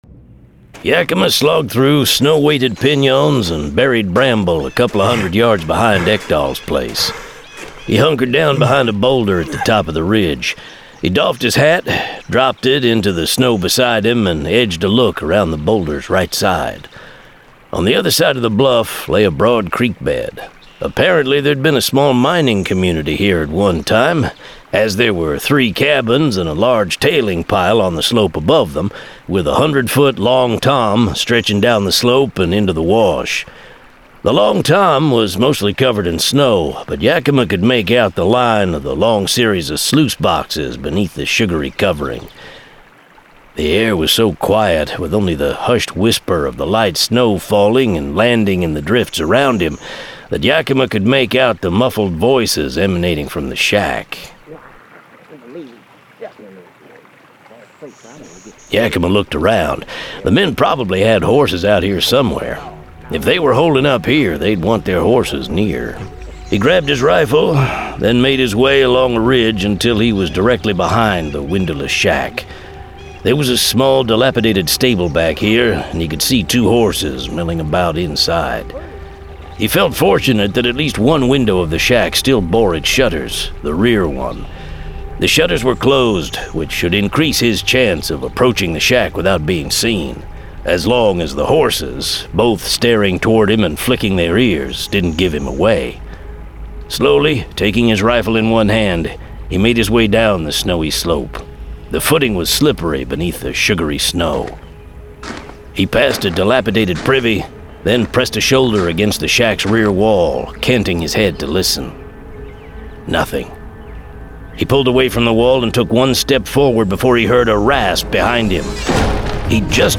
Full Cast. Cinematic Music. Sound Effects.
[Dramatized Adaptation]
Genre: Western